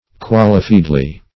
Search Result for " qualifiedly" : The Collaborative International Dictionary of English v.0.48: Qualifiedly \Qual"i*fied`ly\, adv. In the way of qualification; with modification or qualification.